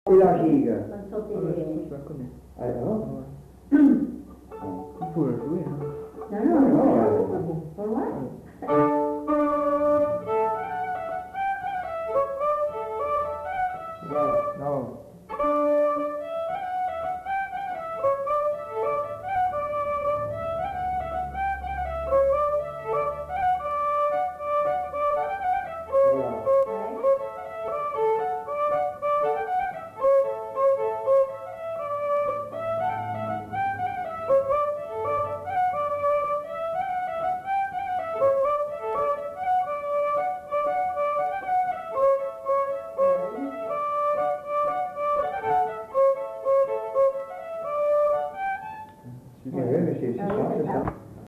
Aire culturelle : Bazadais
Lieu : Bazas
Genre : morceau instrumental
Instrument de musique : violon
Danse : gigue